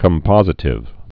(kəm-pŏzĭ-tĭv)